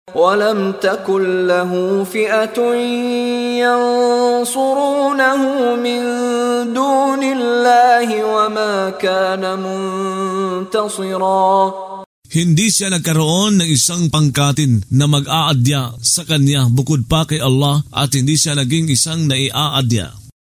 Pagbabasa ng audio sa Filipino (Tagalog) ng mga kahulugan ng Surah Al-Kahf ( Ang Yungib ) na hinati sa mga taludtod, na sinasabayan ng pagbigkas ng reciter na si Mishari bin Rashid Al-Afasy.